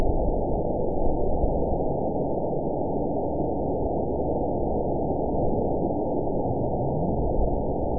event 911459 date 02/28/22 time 22:08:10 GMT (3 years, 8 months ago) score 8.41 location TSS-AB01 detected by nrw target species NRW annotations +NRW Spectrogram: Frequency (kHz) vs. Time (s) audio not available .wav